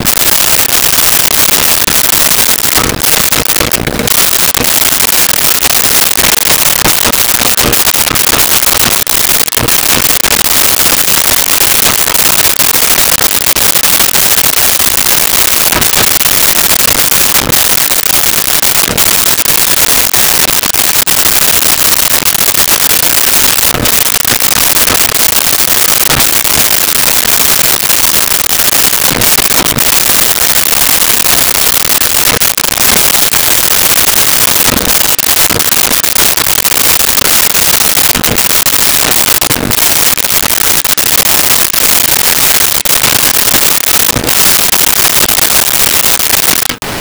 E Typewriter Type Fast
E-Typewriter Type Fast.wav